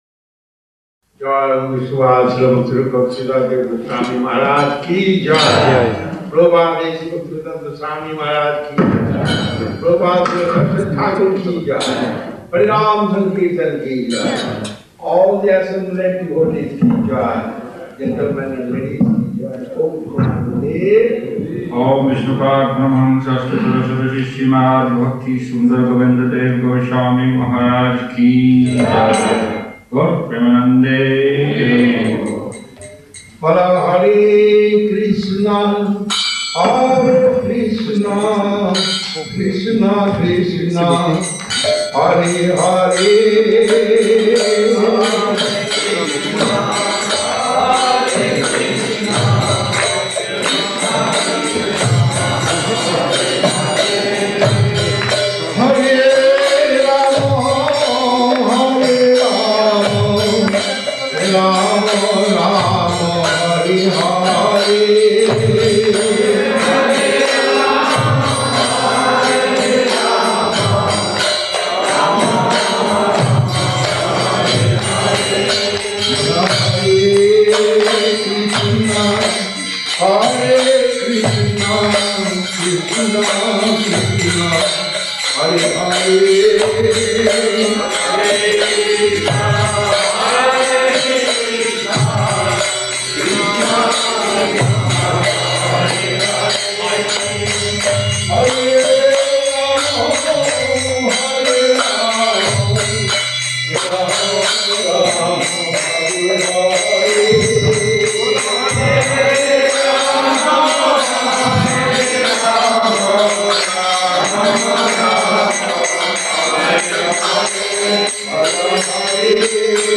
Place: Centre «Sri Chaitanya Saraswati» Moscow
Kirttan